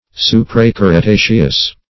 Search Result for " supracretaceous" : The Collaborative International Dictionary of English v.0.48: Supracretaceous \Su`pra*cre*ta"ceous\, a. (Geol.) Lying above the chalk; Supercretaceous.